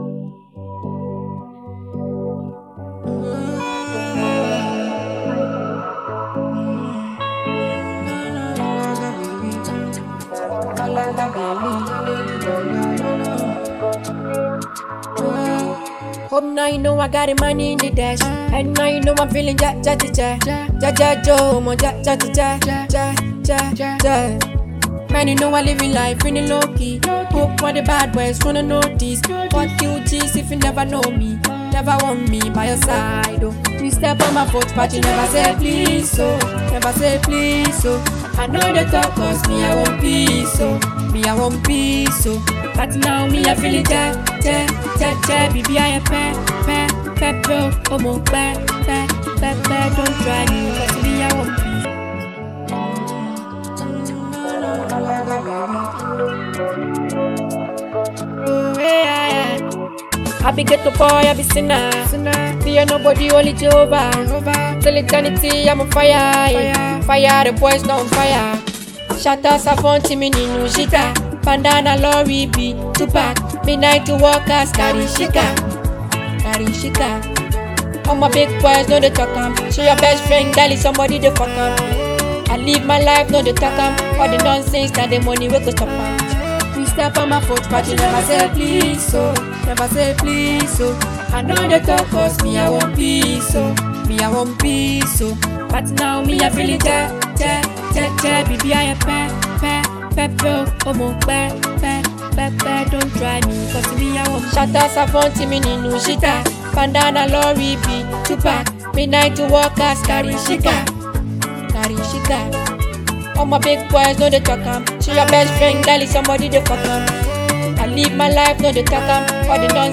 Hot naija afro-sound